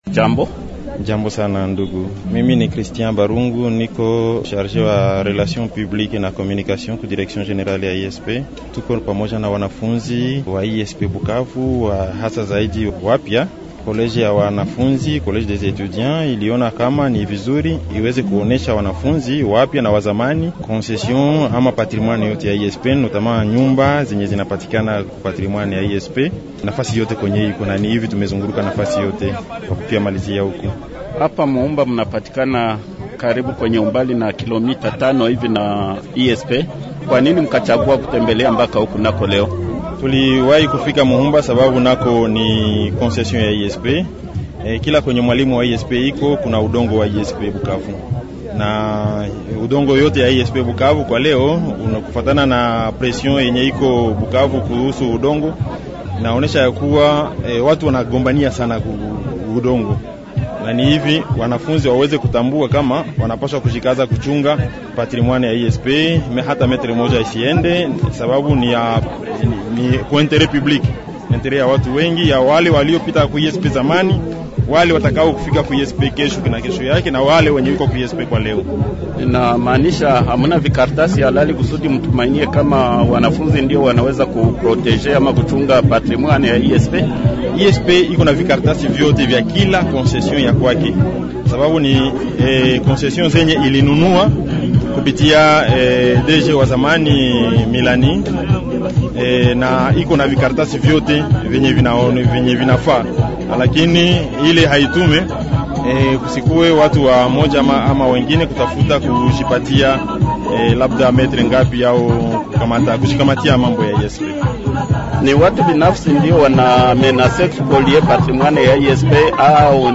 invite_swahili_bukavu_web.mp3